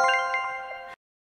correct_fix.mp3